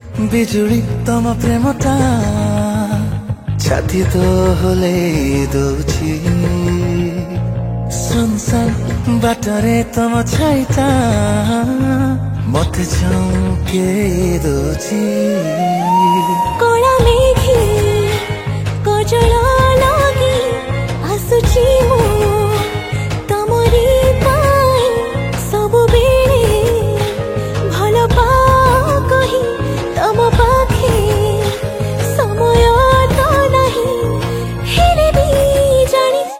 Odia Album Ringtones
Romantic song